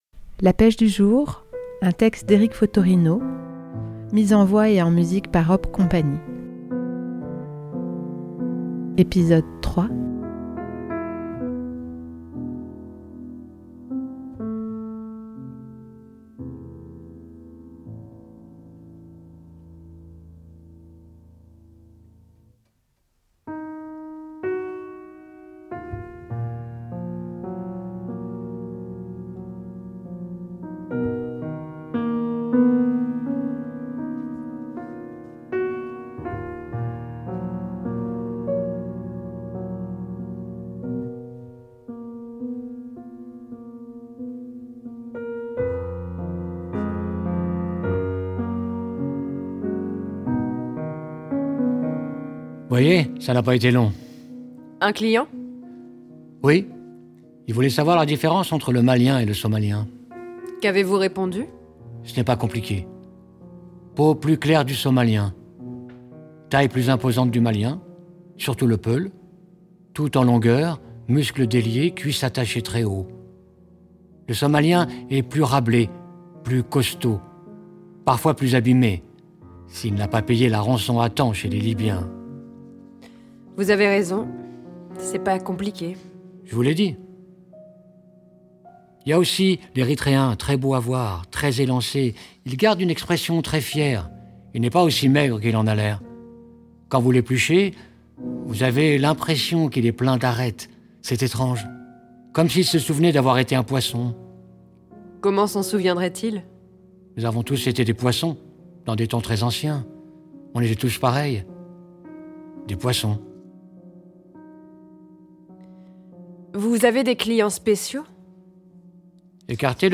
Hop Cie met en scène et en musique le texte coup de poing d'Eric Fottorino "La pêche du jour".